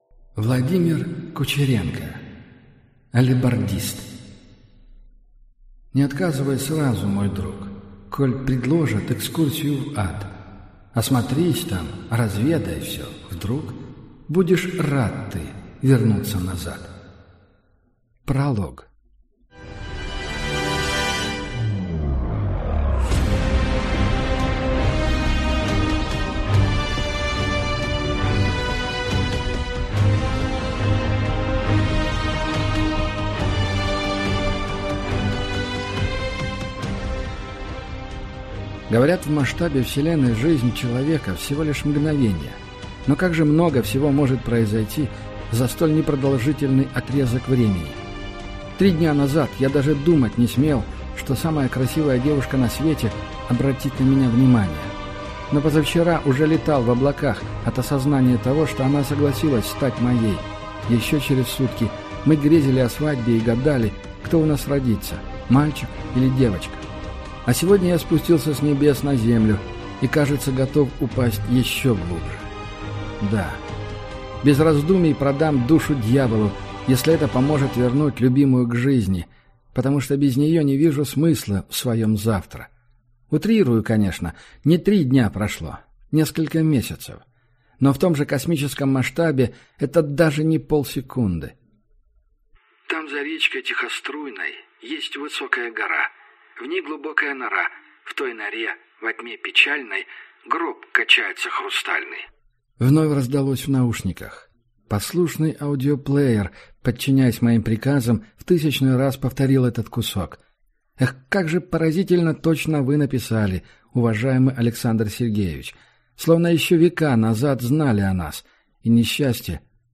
Аудиокнига Алебардист